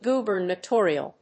音節gu・ber・na・to・ri・al 発音記号・読み方
/g(j)ùːbɚnətˈɔːriəl(米国英語), ˌɡuː.bən.eˈtɔː.ɹi.əl(英国英語)/
gubernatorial.mp3